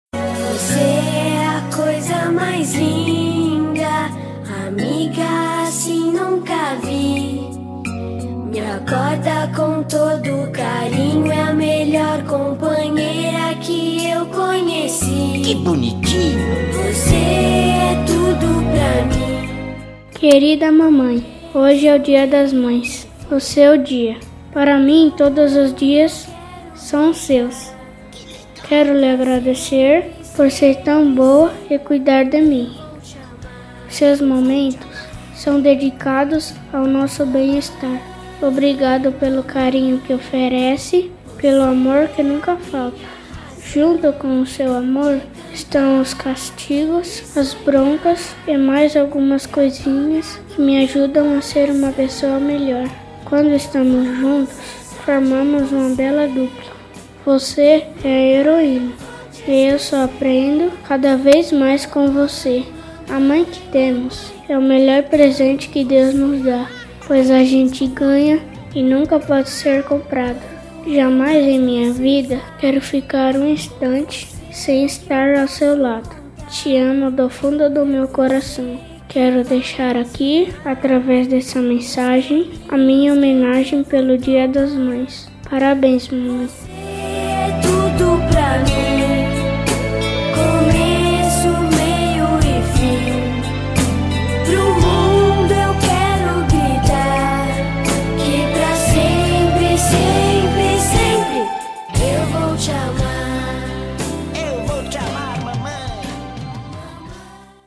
10-FILHO-PARA-MAE-INFANTIL-M-06-Teleco-e-Teco-Homenagem-a-Mamae.mp3